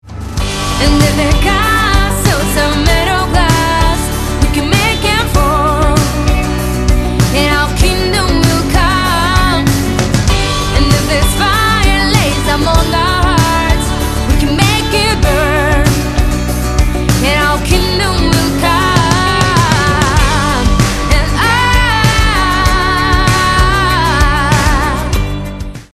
поп
красивые
женский вокал